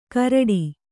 ♪ karaḍi